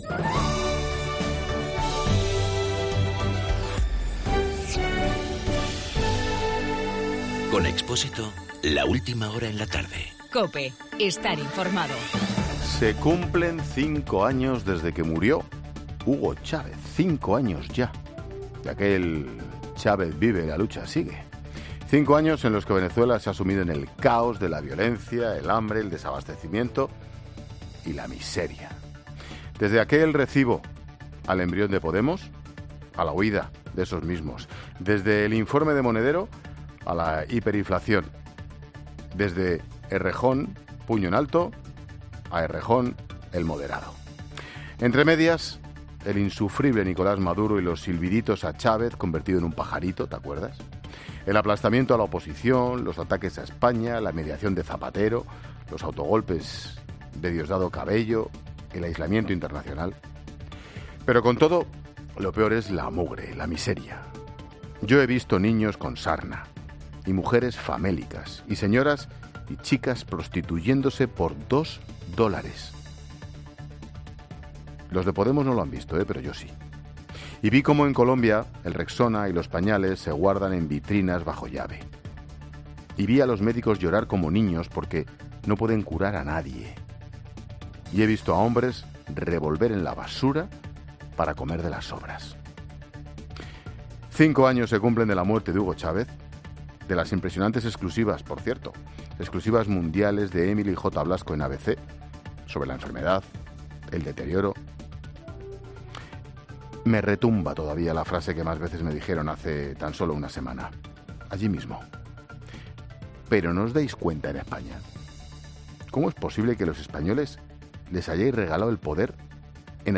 El comentario de Ángel Expósito cuando se cumplen 5 años de la muerte de Hugo Chávez.